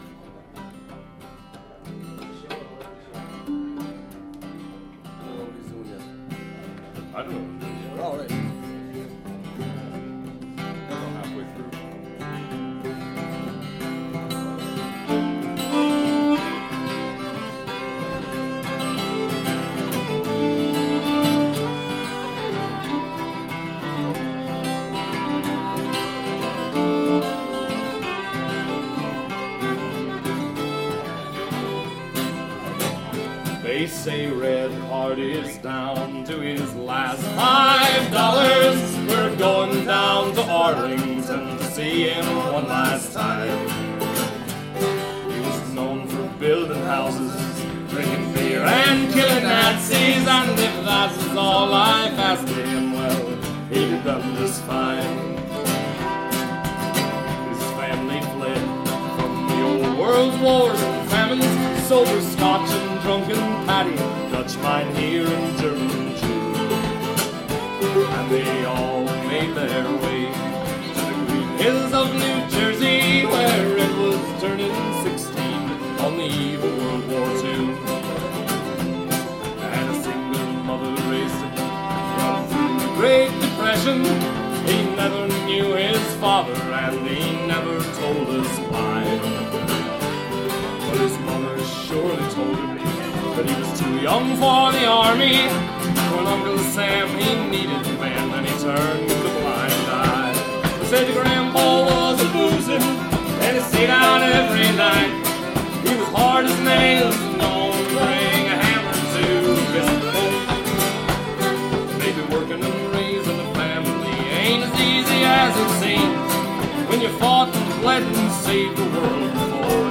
Here are a few songs and tunes from this Sunday's session at Tommy Doyle's.